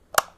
switch25.wav